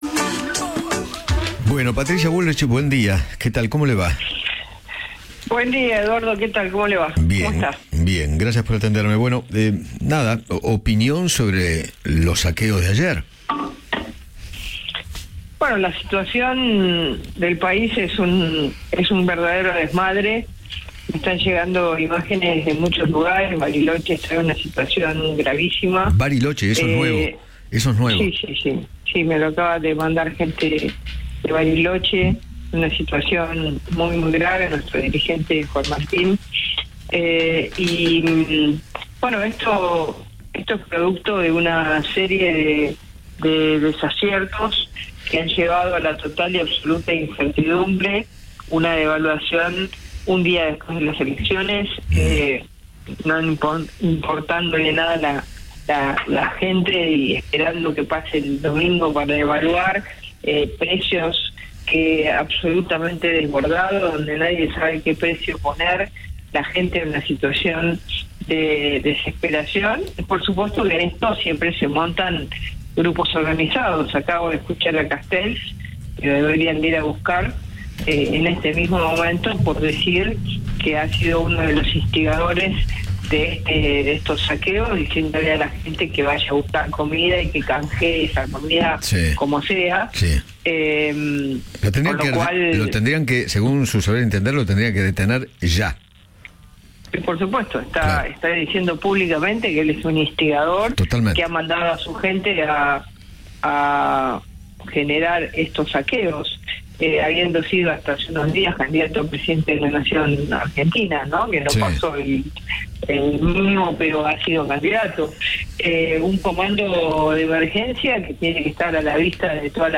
Patricia Bullrich, candidata presidencial por Juntos por el Cambio, habló con Eduardo Feinmann sobre el accionar del Gobierno ante los saqueos y sostuvo que se debería armar un comando de emergencia para evitar los robos.